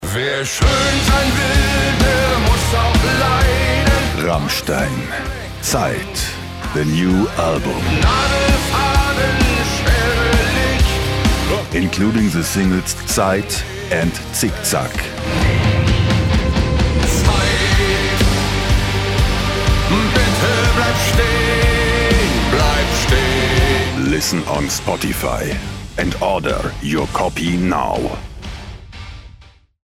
dunkel, sonor, souverän, markant, sehr variabel, plakativ
Norddeutsch
Commercial (Werbung)